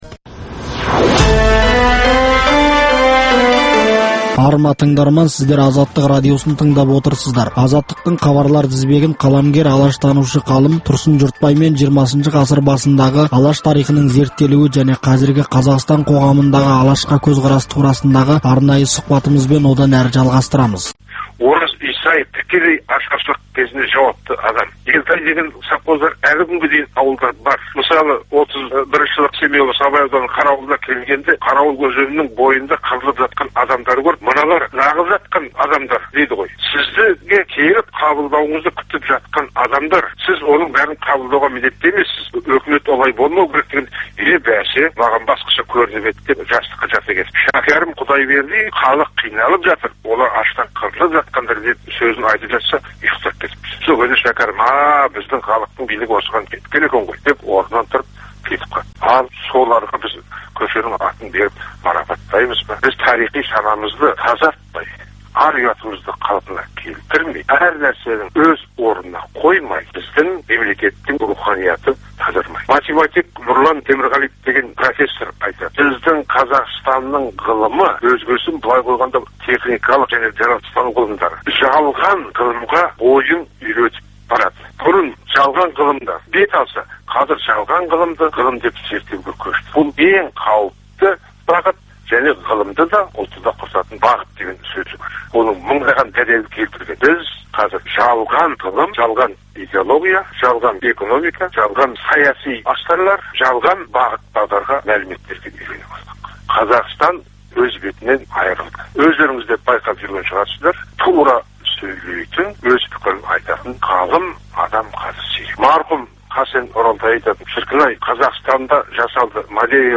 Өткен тарихты зерделеу, ақтаңдақтар мен ұлт тарихындағы қиын-қыстау күндердің бүгінгі тарихта бағалануы тұрғысында тарихшы – зерттеушілермен өткізілетін сұхбат, талдау хабарлар.